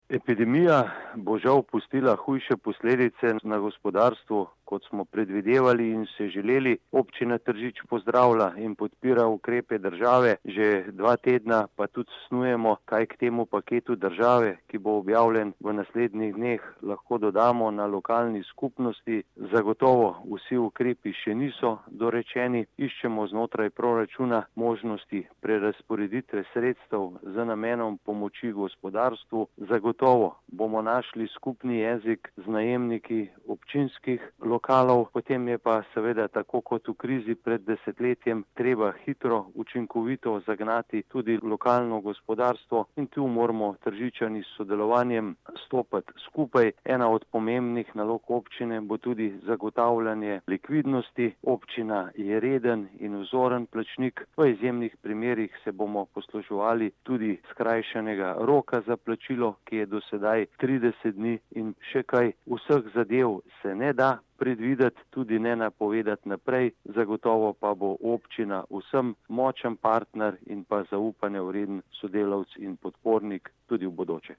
Vsi mi pa lahko pomagamo tako, da po koncu krize povečamo potrošnjo in kupujemo lokalno,« pravi mag. Borut Sajovic, župan Občine Tržič.
izjava_mag.borutsajoviczupanobcinetrzic_pomocgospodarstvu.mp3 (1,8MB)